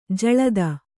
♪ jaḷada